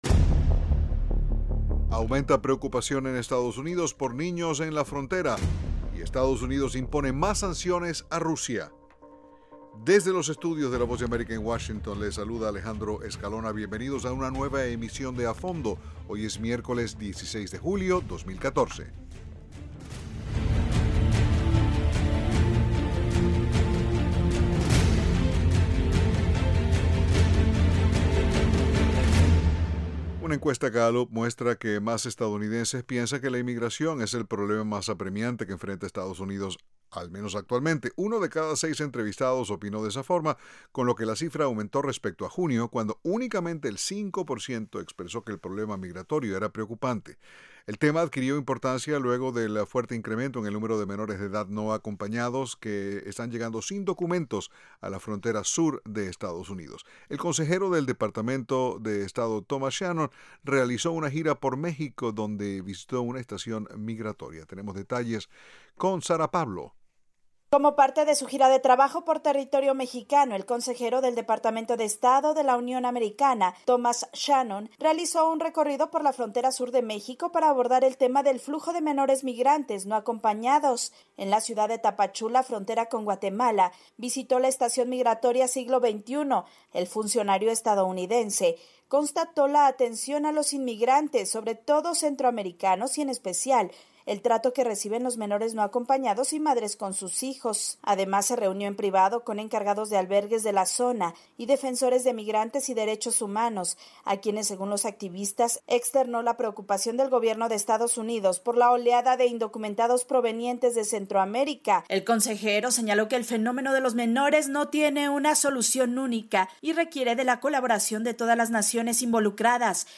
De lunes a viernes, a las 8:00pm [hora de Washington], un equipo de periodistas y corresponsales analizan las noticias más relevantes.